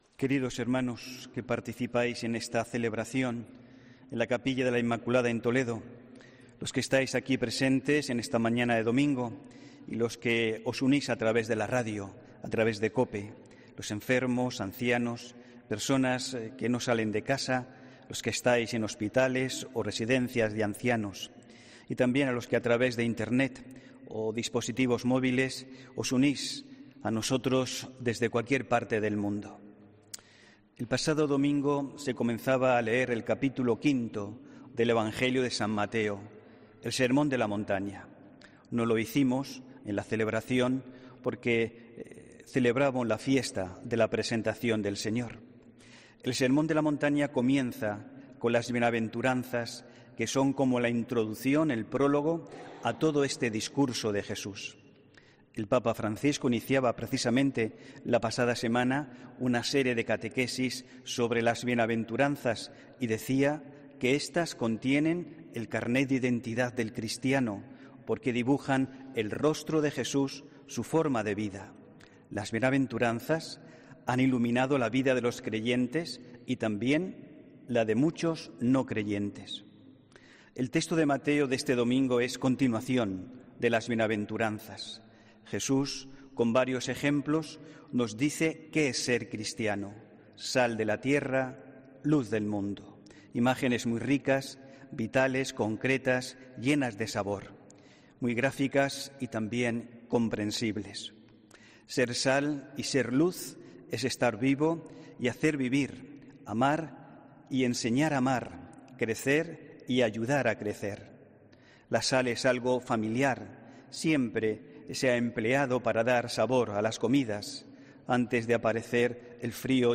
HOMILÍA 9 FEBRERO 2020